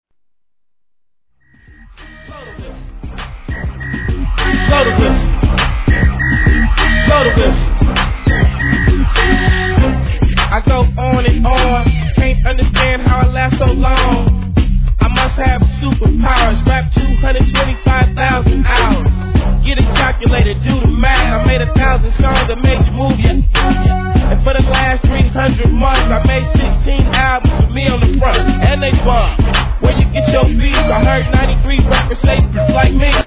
G-RAP/WEST COAST/SOUTH
(100 BPM)